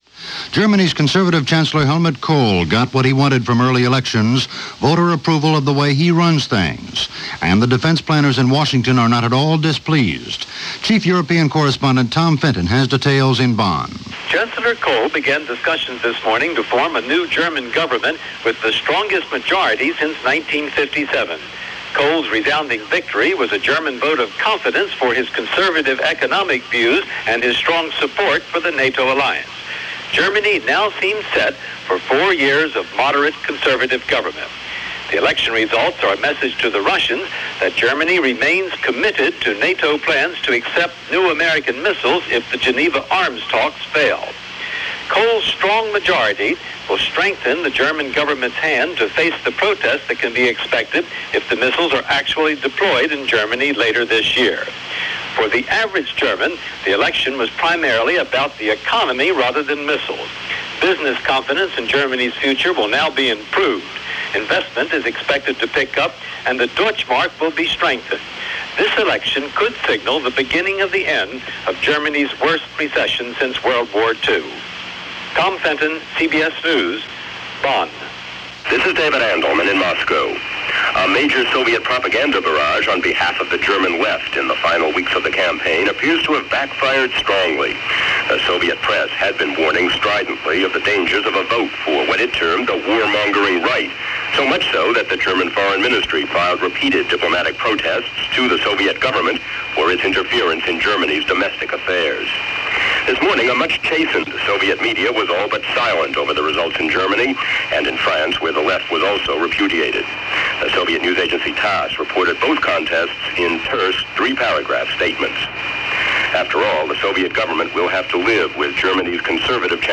CBS World News Roundup + Newsbreak + 9:00 am News